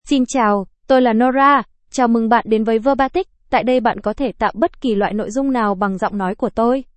NoraFemale Vietnamese AI voice
Nora is a female AI voice for Vietnamese (Vietnam).
Voice sample
Female
Nora delivers clear pronunciation with authentic Vietnam Vietnamese intonation, making your content sound professionally produced.